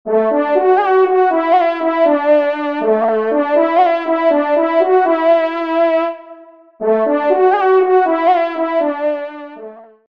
FANFARE
Auteur : Edgard de Montlibert
Equipage : Rallye Pierre / Rallye Deux-Pierre